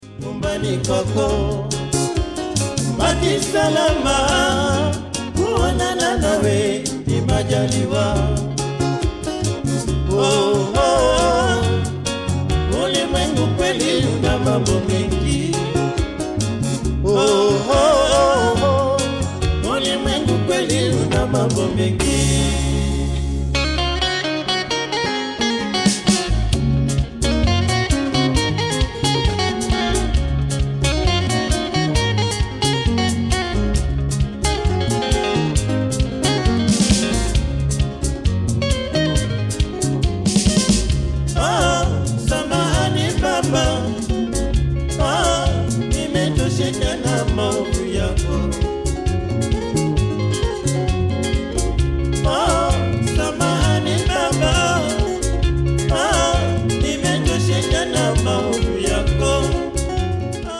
recorded this album in Holland in 1991
extra guitar and percussion being added later